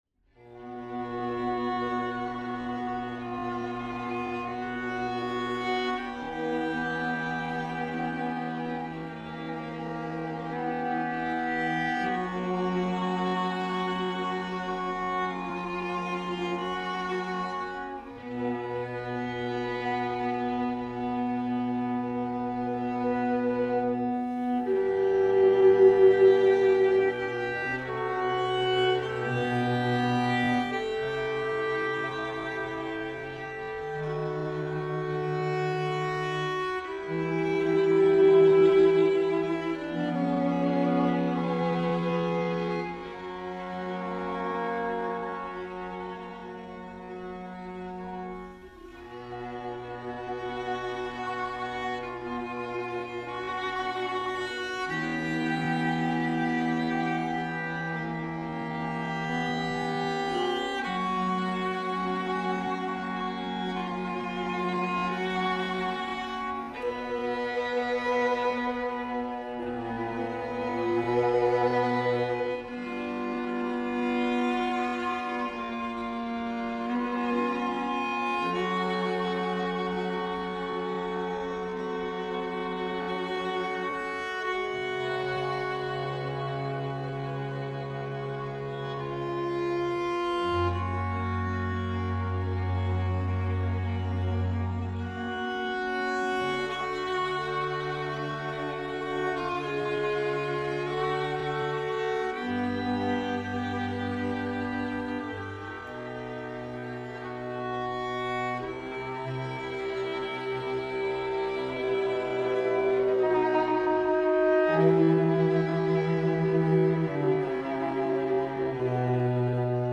It is squarely in the late Viennese Classical style.